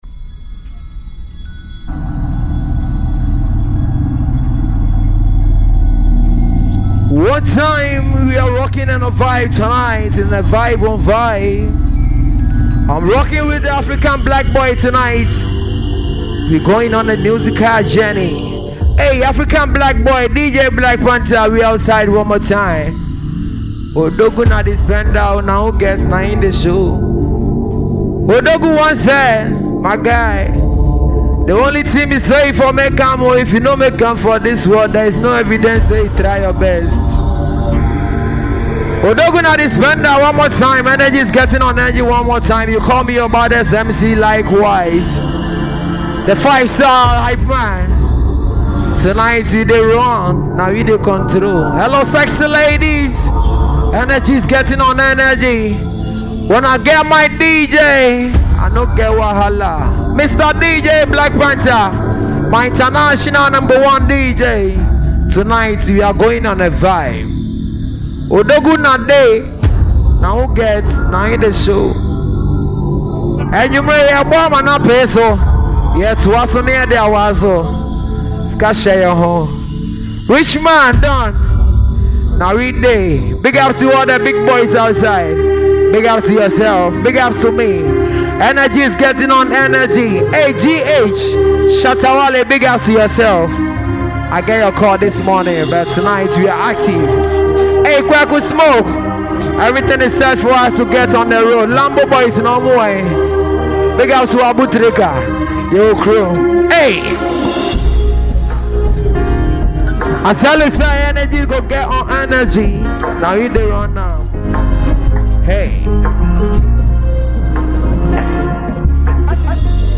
adding lively shouts and crowd-pumping energy